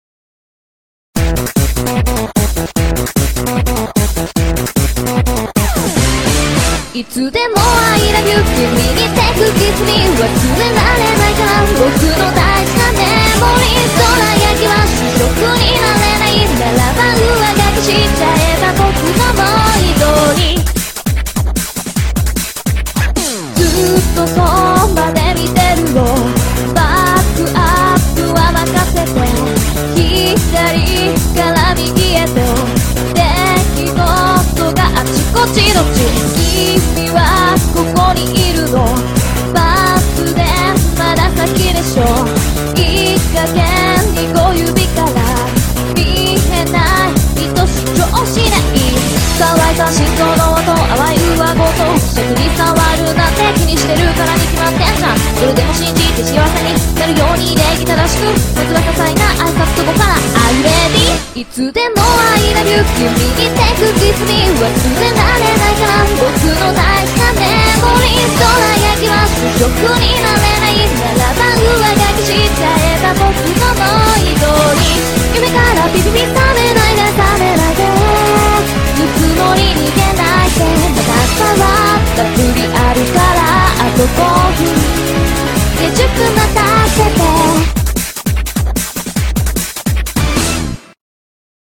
BPM150
Audio QualityCut From Video